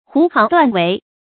胡行乱为 hú xíng luàn wéi
胡行乱为发音